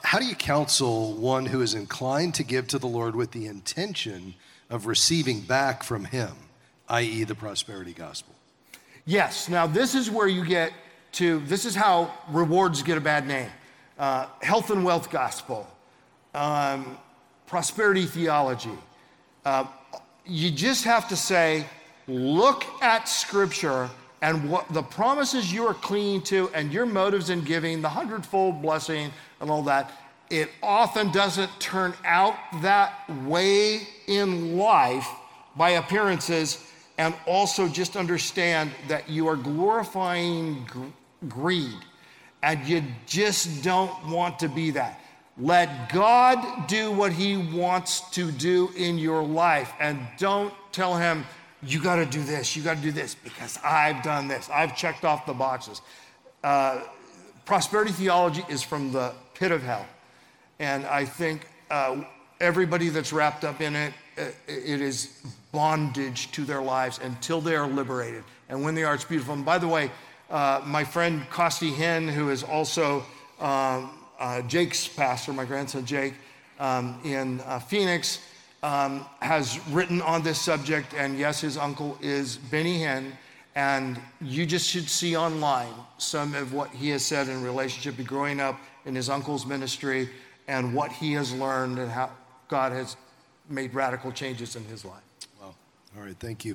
In this clip from a Q&A at the Kingdom Advisors Conference